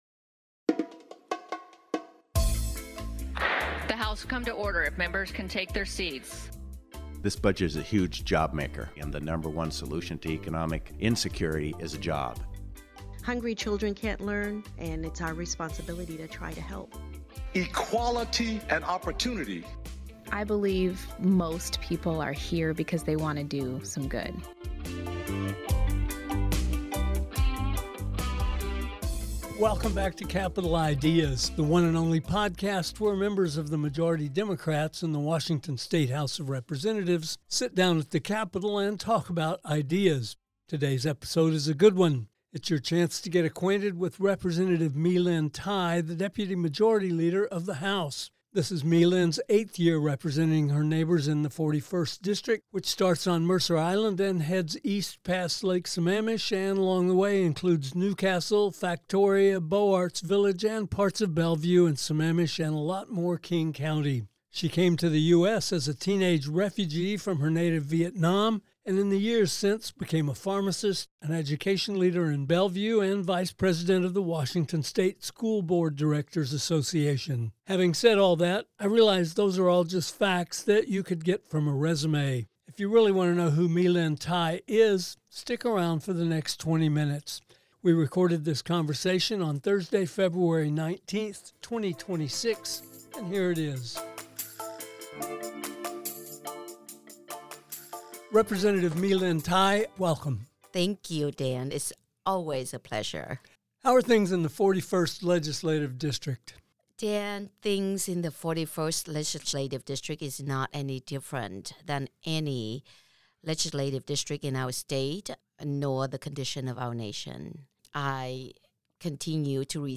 Today’s visitor to Capitol Ideas is Rep. My-Linh Thai, deputy majority leader in the state House of Representatives